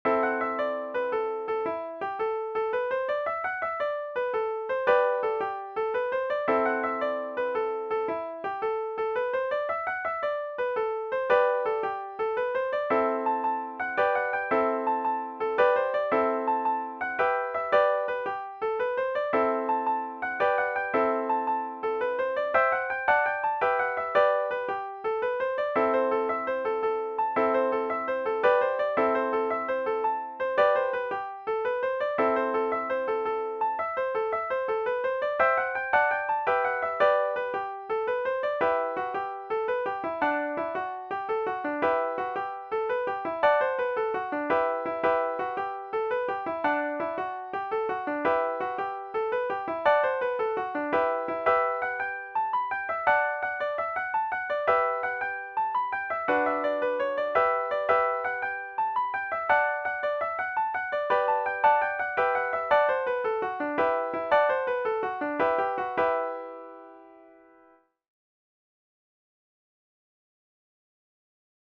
VIOLIN SOLO, DUET or ENSEMBLE Violin Solo
Celtic/Irish